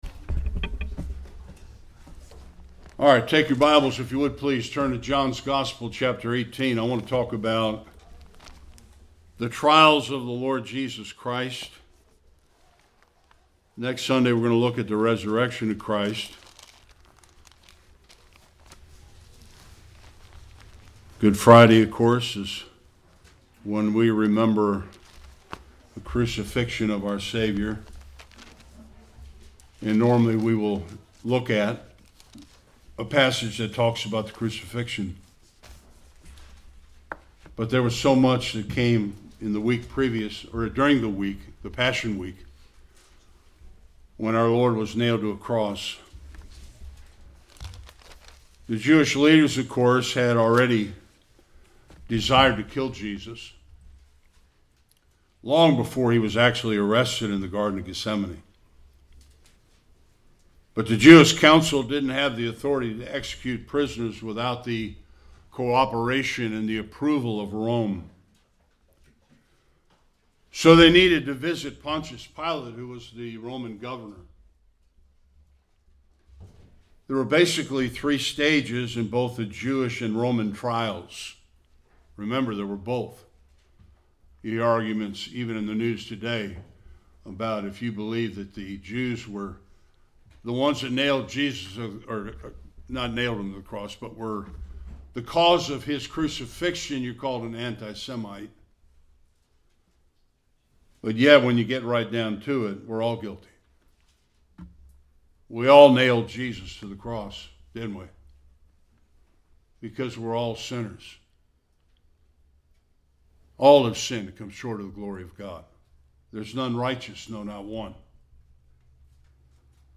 John 18 Service Type: Sunday Worship Judas betrayed Jesus and he was arrested in the Garden of Gethsemane.